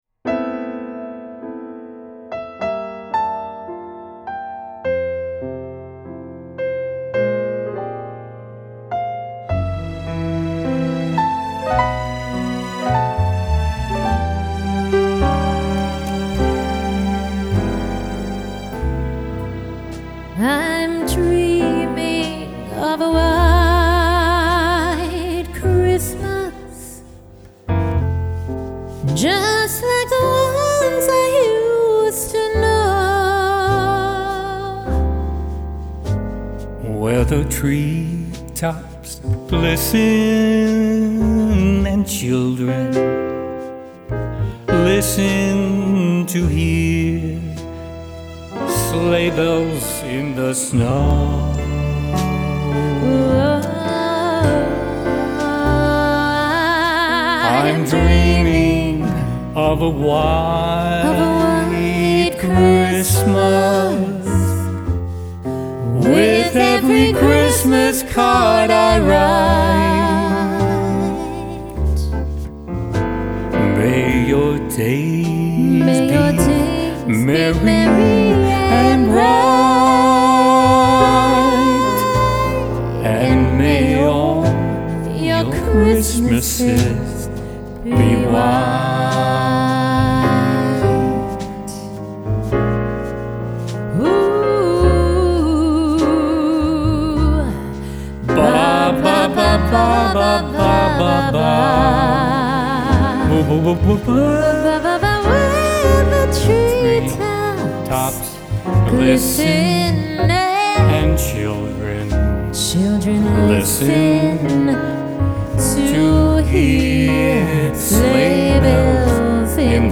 Genre : Christmas Music